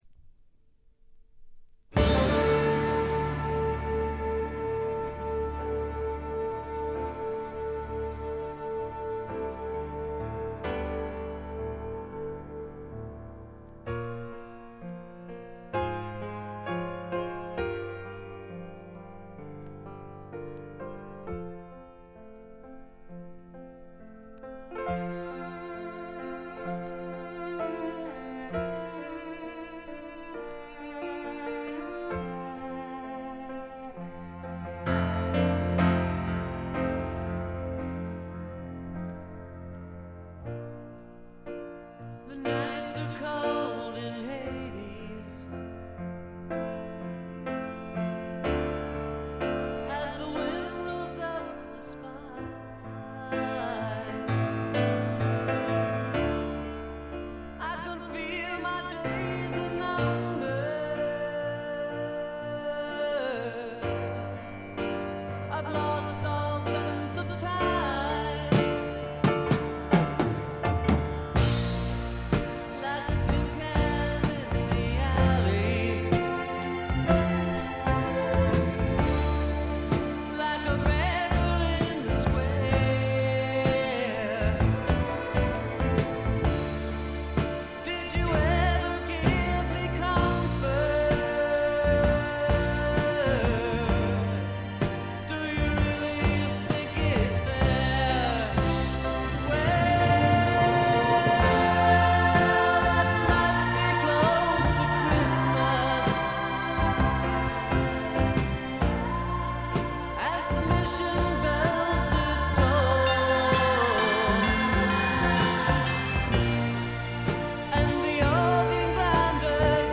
ほとんどノイズ感無し。
パワー・ポップ、ニッチ・ポップ好きにもばっちりはまる傑作です。
試聴曲は現品からの取り込み音源です。
Lead Vocals, Guitar, Piano